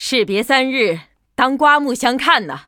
文件 文件历史 文件用途 全域文件用途 Lobato_amb_03.ogg （Ogg Vorbis声音文件，长度2.7秒，104 kbps，文件大小：34 KB） 文件说明 源地址:游戏语音 文件历史 点击某个日期/时间查看对应时刻的文件。 日期/时间 缩略图 大小 用户 备注 当前 2018年11月17日 (六) 03:33 2.7秒 （34 KB） 地下城与勇士  （ 留言 | 贡献 ） 分类:洛巴赫 分类:地下城与勇士 源地址:游戏语音 您不可以覆盖此文件。